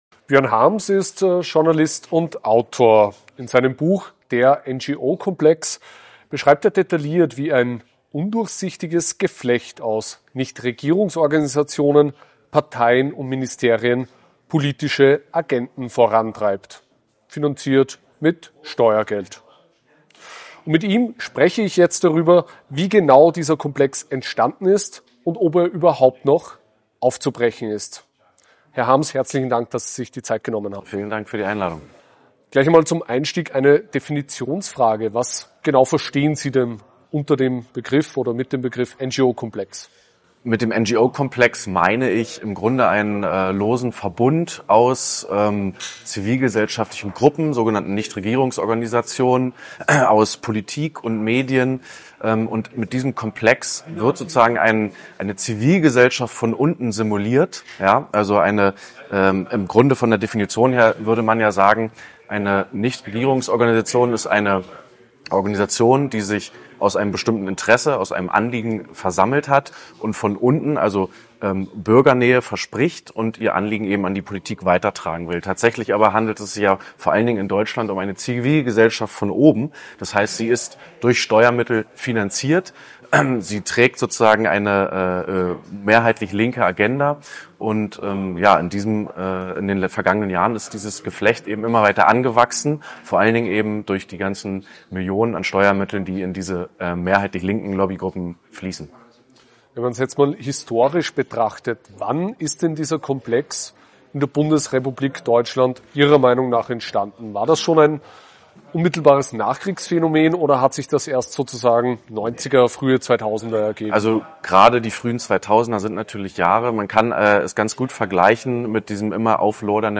Im AUF1-Interview erklärt er, wie staatliche Gelder gezielt genutzt werden, um linke Ideologie zu fördern und oppositionelle Stimmen zu schwächen. Und er schildert, welche Rolle dabei der "tiefe Staat" spielt und warum auch vermeintlich konservative Parteien wie die CDU diesen Komplex unangetastet lassen.